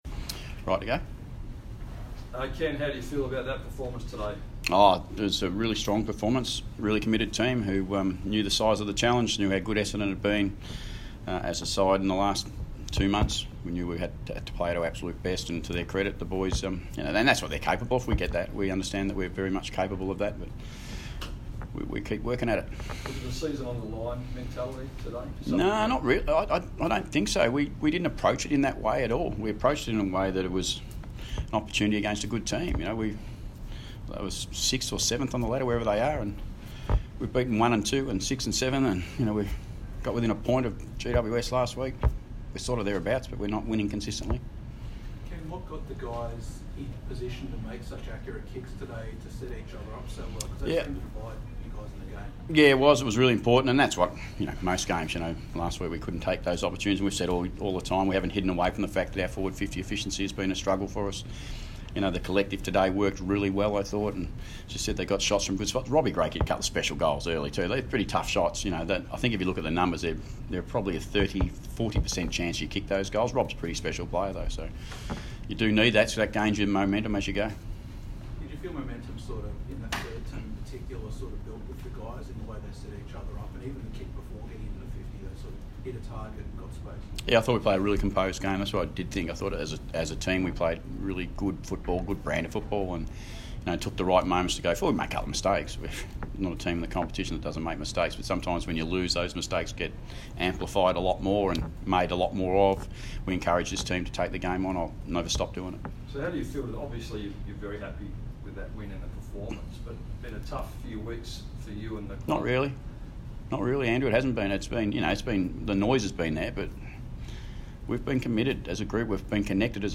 Ken Hinkley press conference - Saturday 3 August, 2019